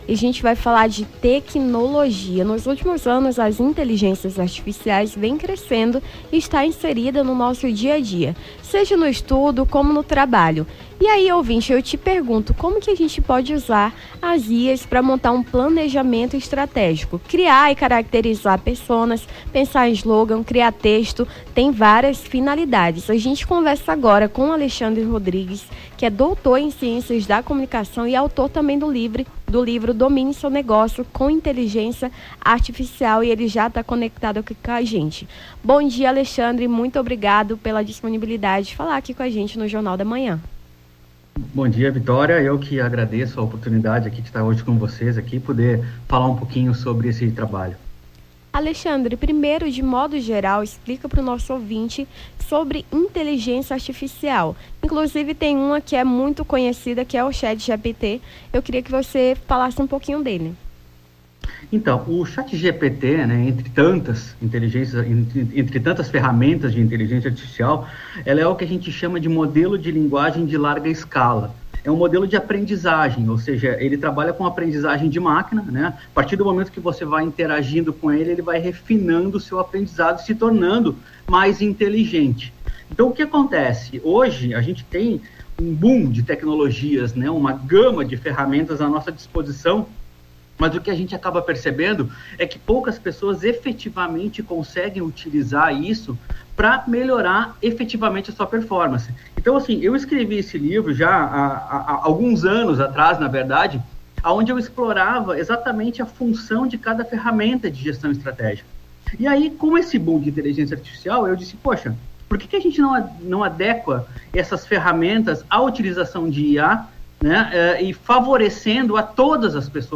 Nome do Artista - CENSURA - ENTREVISTA CHATGPT PLANEJAMENTO (16-05-24).mp3